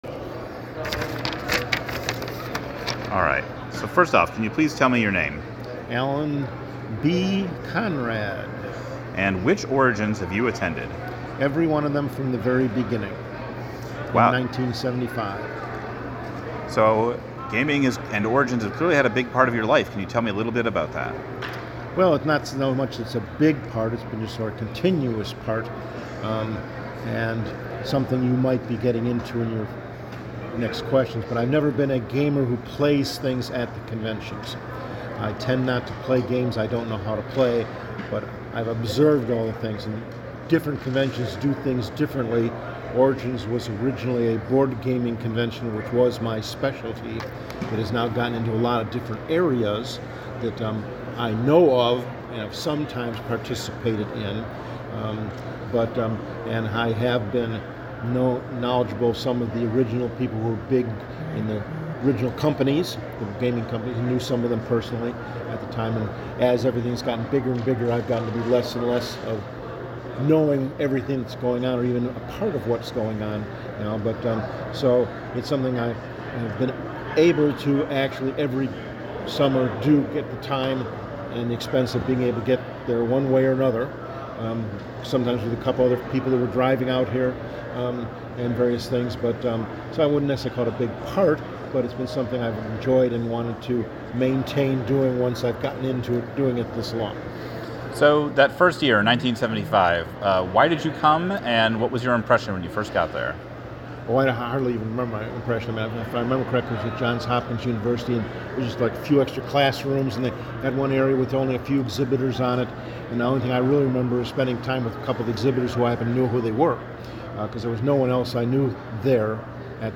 Origins Oral Histories